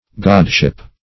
Search Result for " godship" : The Collaborative International Dictionary of English v.0.48: Godship \God"ship\, n. [God, n. + -ship.] The rank or character of a god; deity; divinity; a god or goddess.
godship.mp3